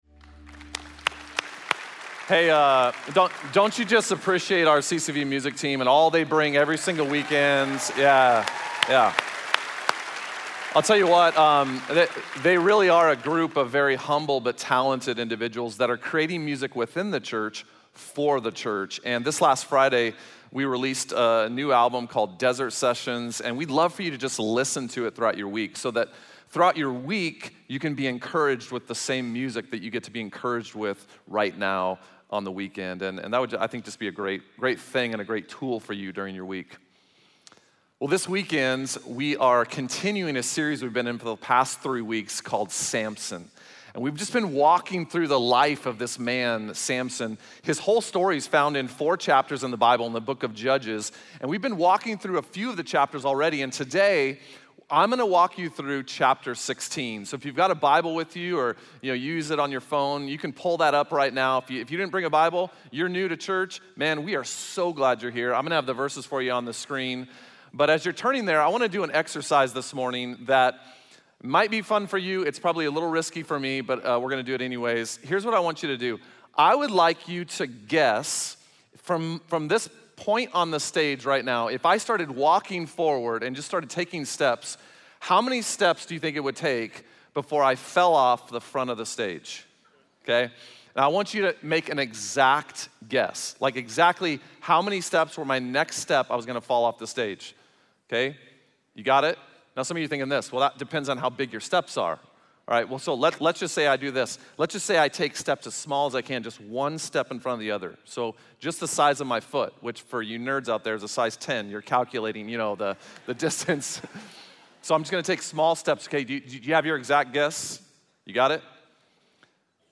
Message Only